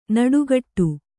♪ naḍuṭṭu